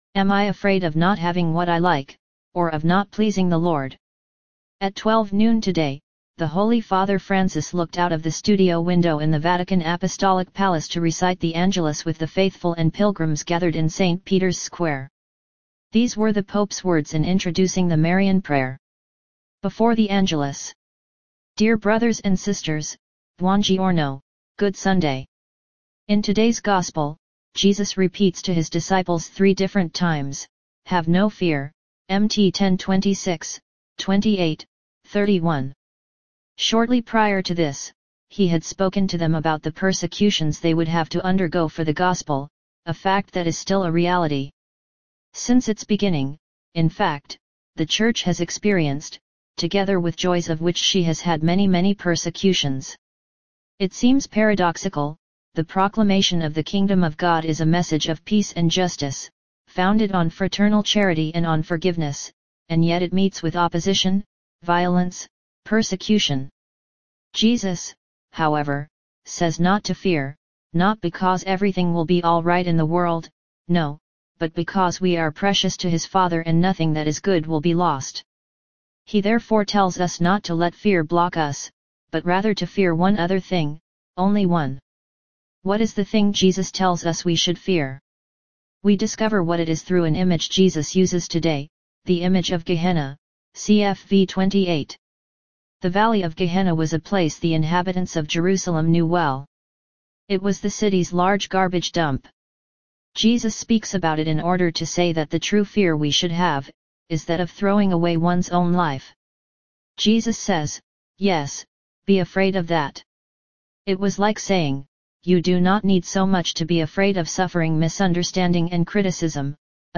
The Holy Father’s words before the Angelus
At 12 noon today, the Holy Father Francis looked out of the studio window in the Vatican Apostolic Palace to recite the Angelus with the faithful and pilgrims gathered in St. Peter’s Square.